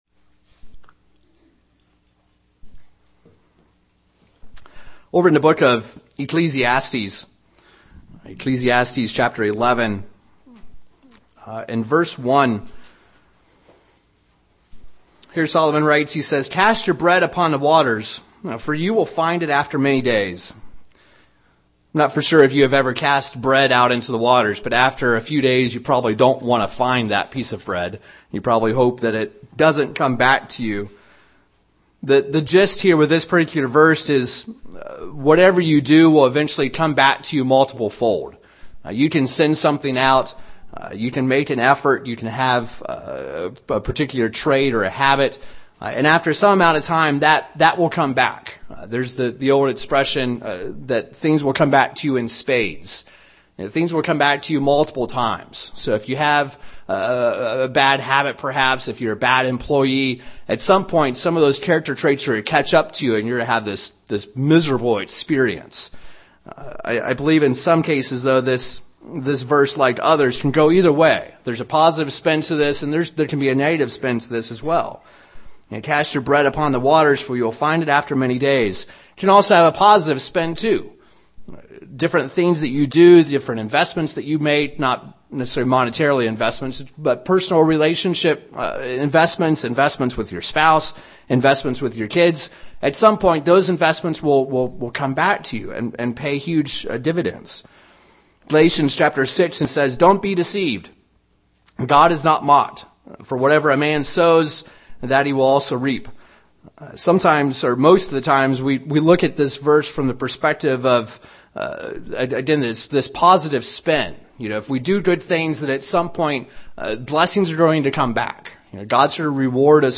Given in Wichita, KS